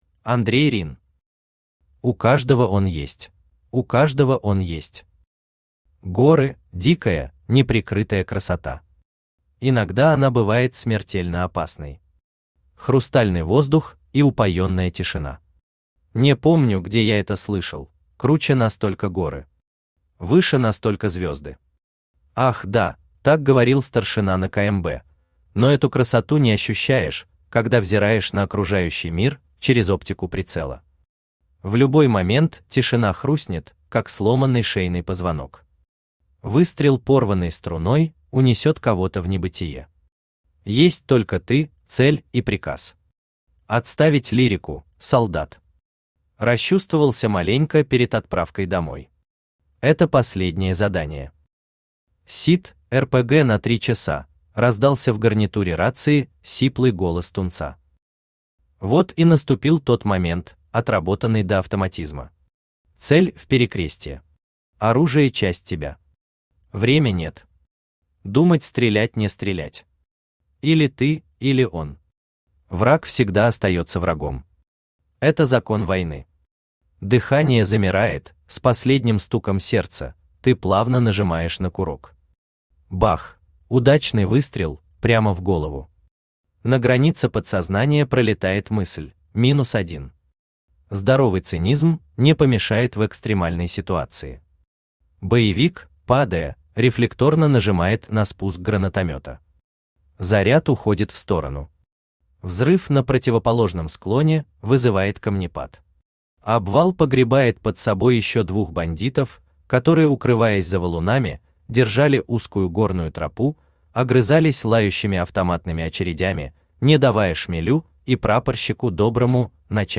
Аудиокнига У каждого он есть…
Автор Андрей Рин Читает аудиокнигу Авточтец ЛитРес.